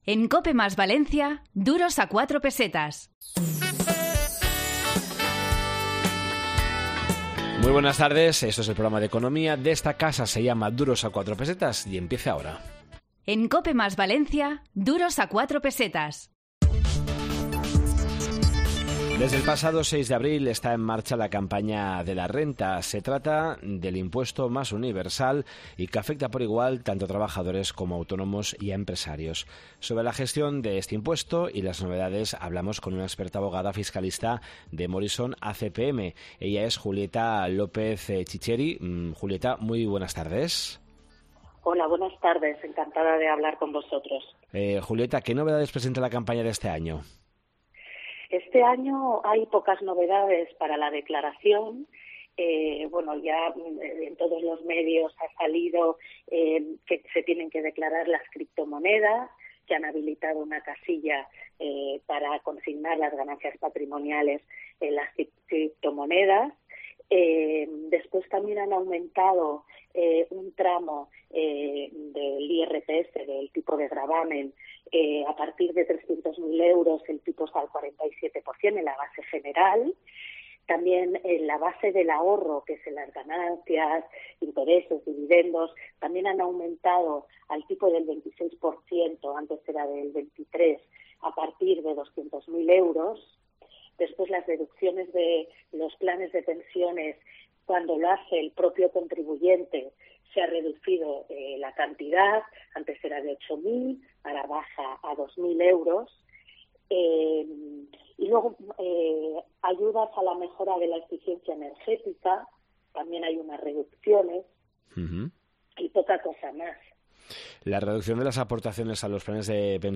Esta semana en Duros a 4 Pesetas de COPE + Valencia, en el 92.0 de la FM, hemos preparado un programa dedicado a la campaña de la renta, la Silver Economy y los salones de juego.